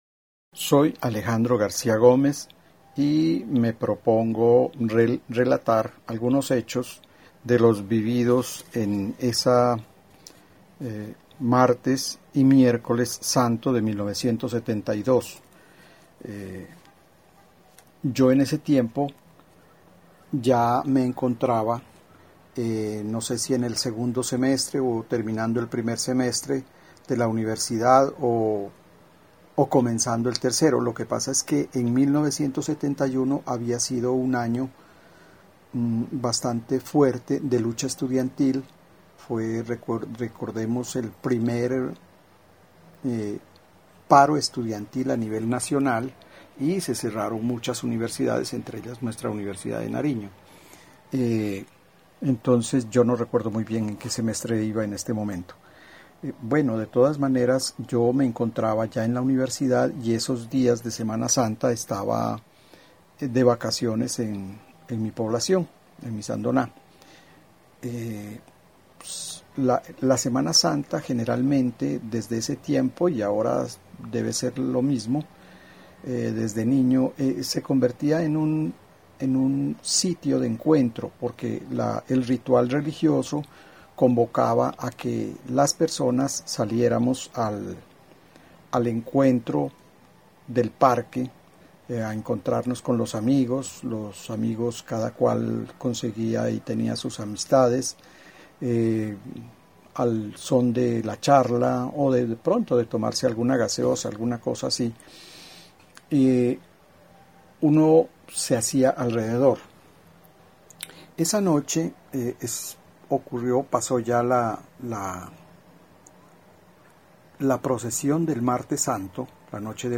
Relato de la Semana Santa de 1972